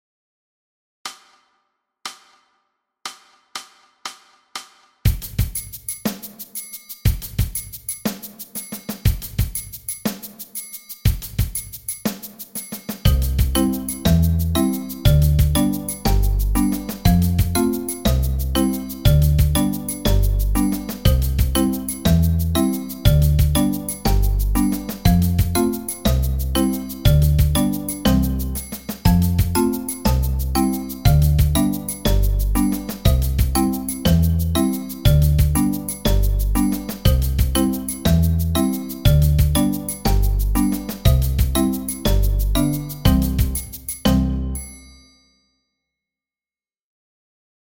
Danses de l’ours d’or – accompagnement avec xylo à 120 bpm
Danses-de-lours-dor-accompagnement-avec-xylo-a-120-bpm.mp3